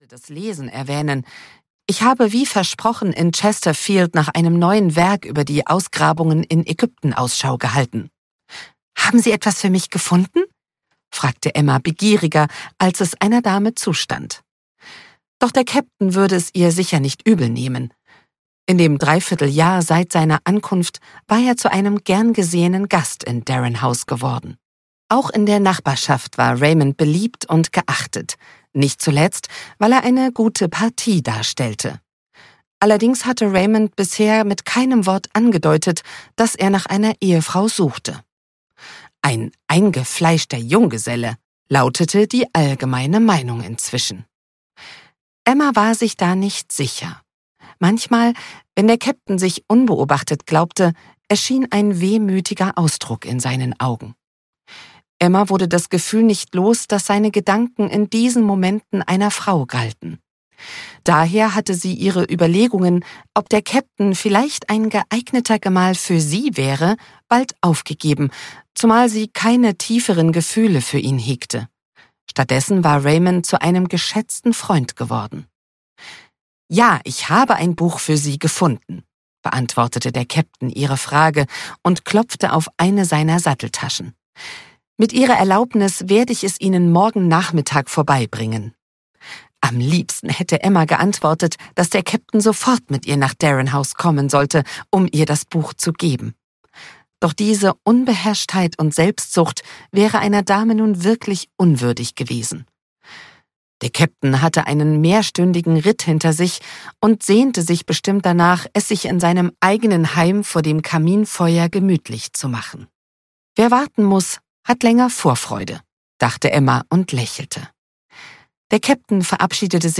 Eine Lady in Not (DE) audiokniha
Ukázka z knihy